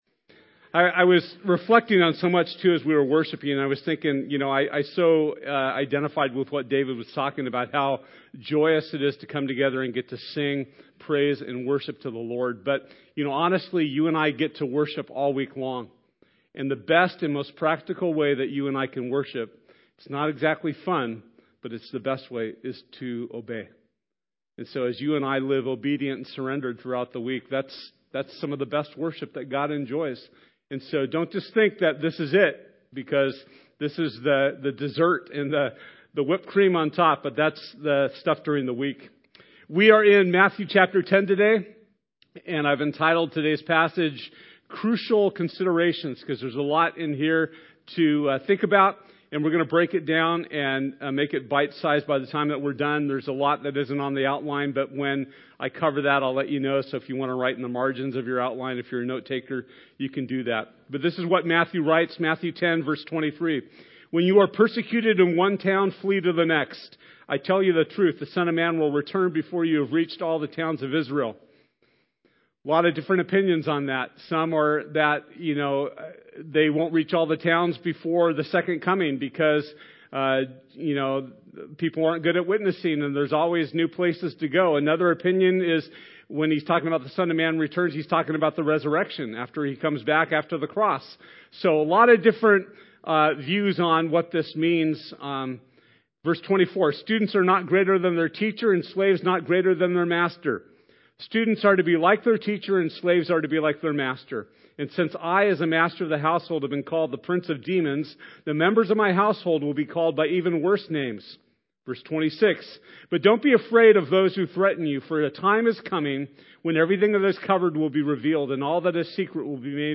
Matthew 10:23-42 Service Type: Sunday This Sunday our teaching passage will be Matthew 10:23-42.